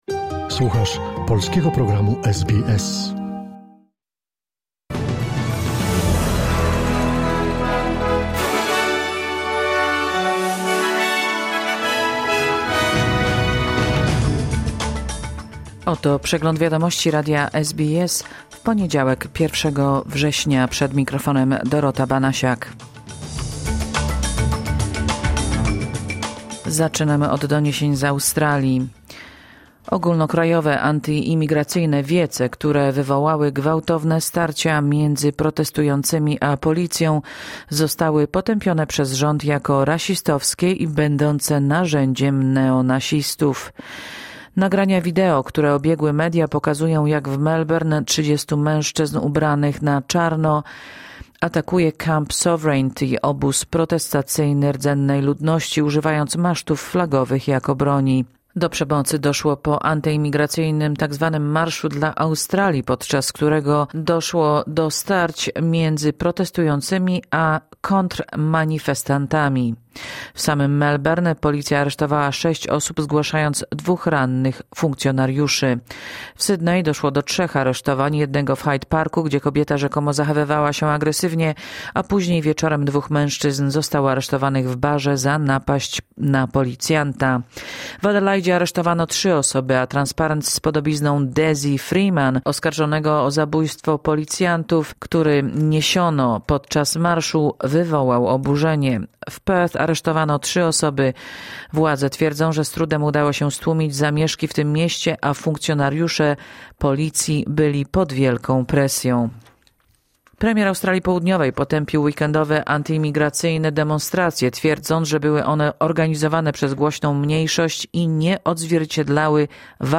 Wiadomości 1 września SBS News Flash